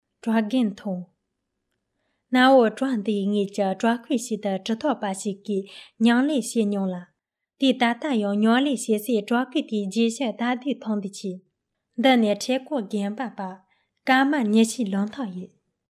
藏语配音|翻译|字幕|视频制作
藏语样音试听下载